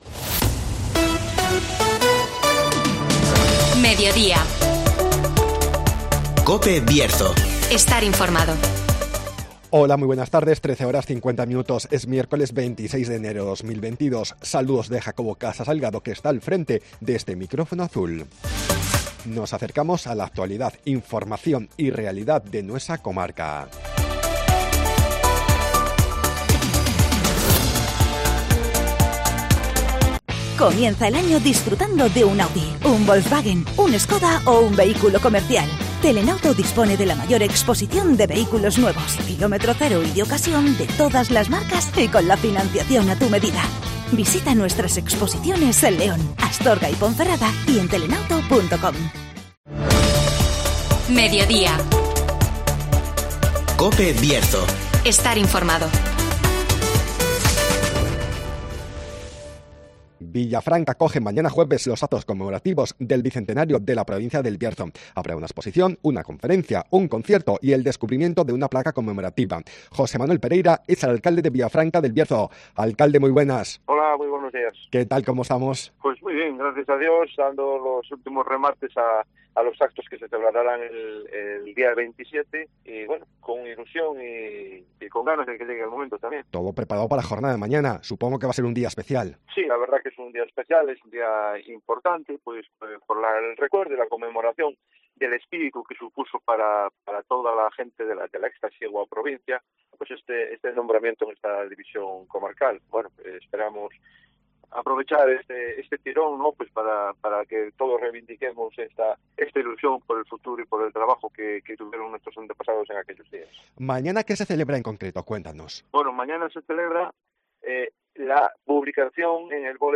Villafranca del Bierzo acoge este jueves los actos conmemorativos del bicentenario de la provincia (Entrevista al alcalde José Manuel Pereira)